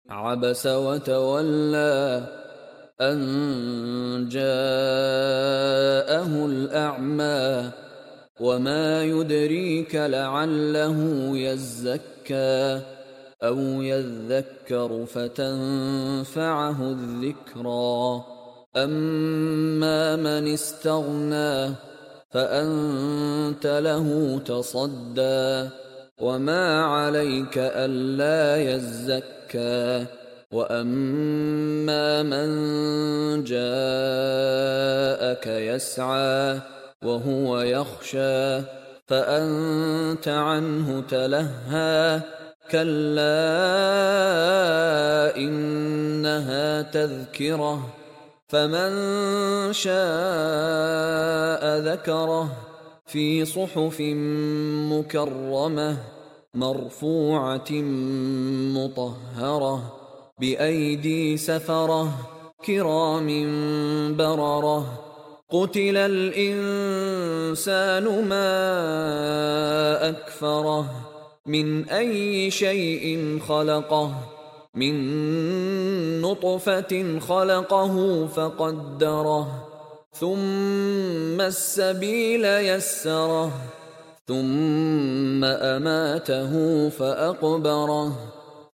Surah 'Abasa Ayat(1-21) Listen peaceful tilawat